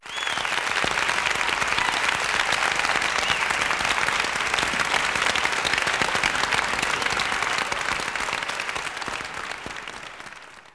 clap_020.wav